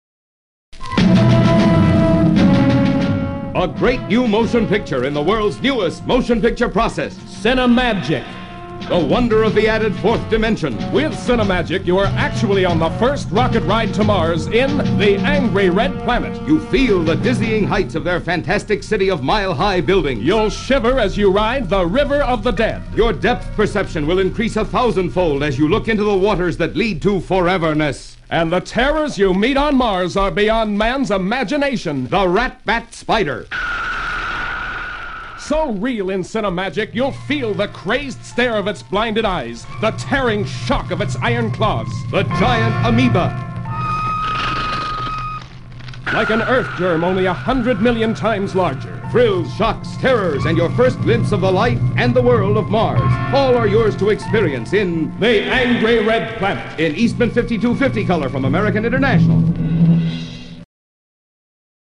The Angry Red Planet (1959) Radio Spots
I was somewhat surprised to see that American International’s publicity department released only two radio spots for it – a sixty and a thirty.